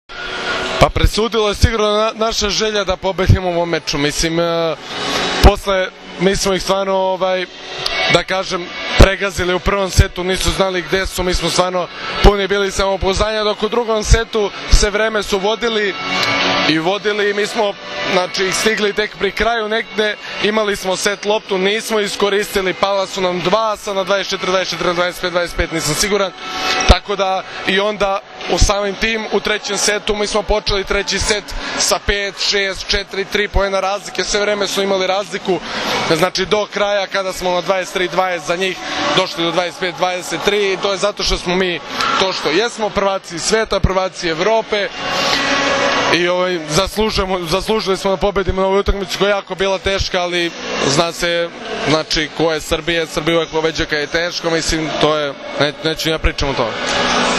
IZJAVA UROŠA KOVAČEVIĆA, KAPITENA SRBIJE